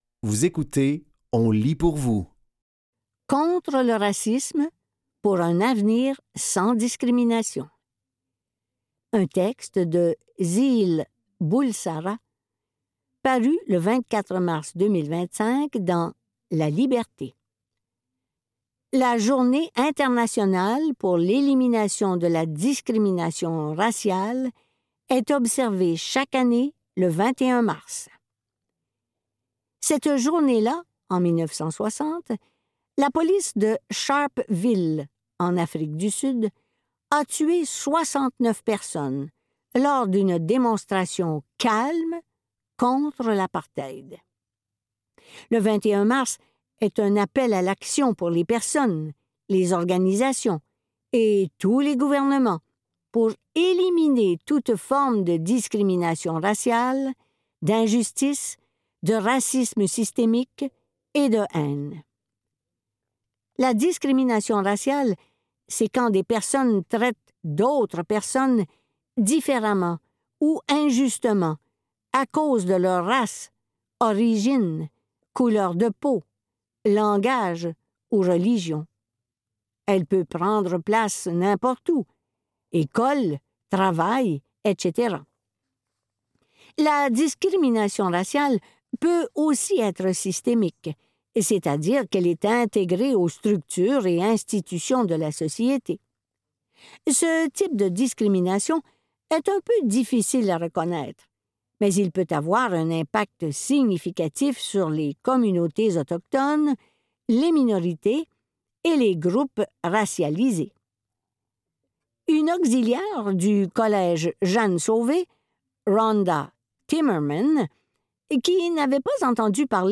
Dans cet épisode de On lit pour vous, nous vous offrons une sélection de textes tirés des médias suivants : La Liberté, La Presse et Le Devoir.